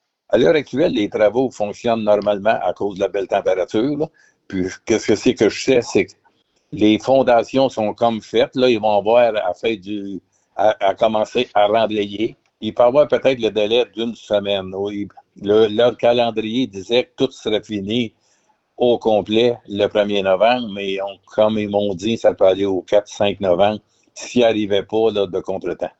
Le maire a résumé les travaux qui se passent dans sa municipalité.